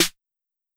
Snare (Hyyerr).wav